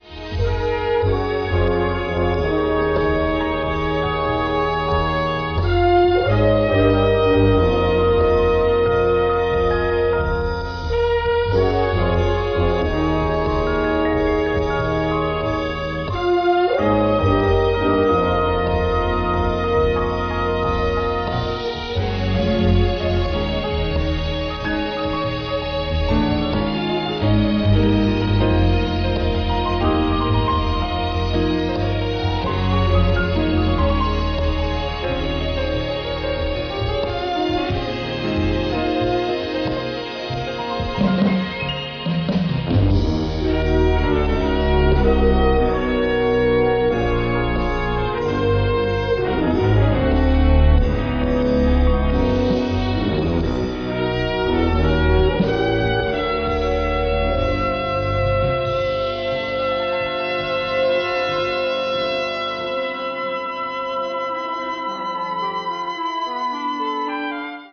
innovative use of synthesizers combined with jazz elements